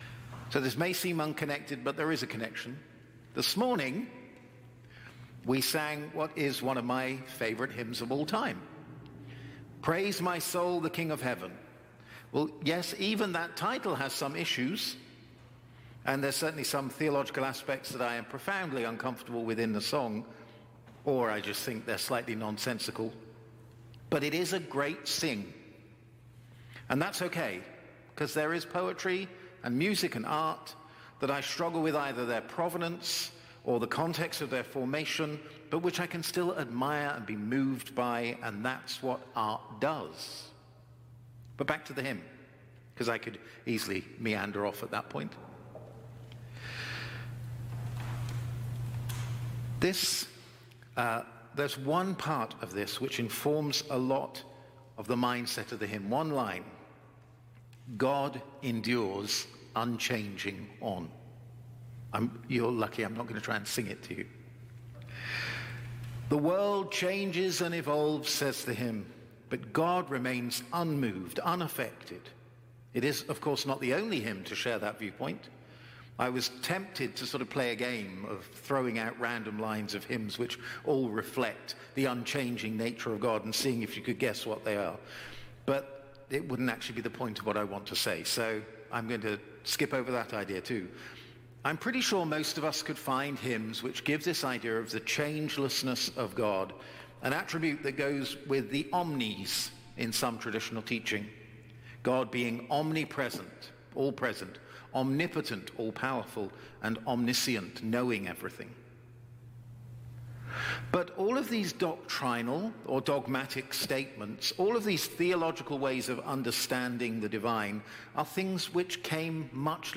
Sermons | St. John the Divine Anglican Church